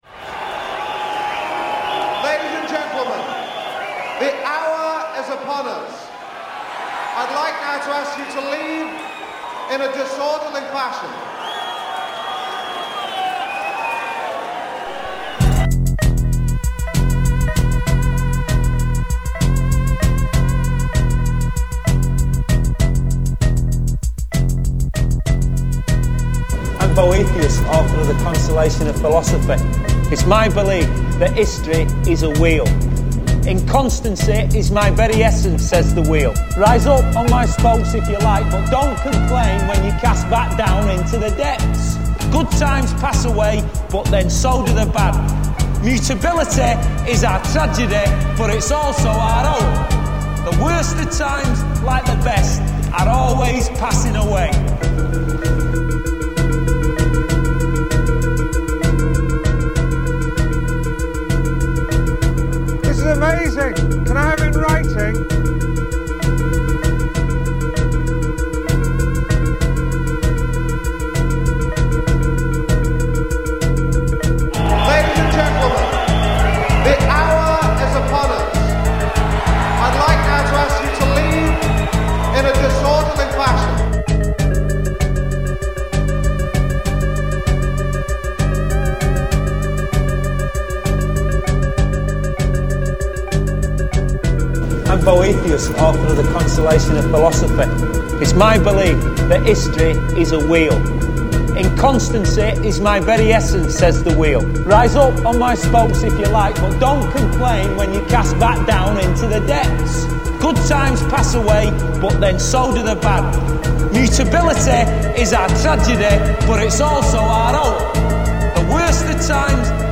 Sommige nummers uit een grijs en ver verleden, dus de geluidskwaliteit is af en toe wat minder.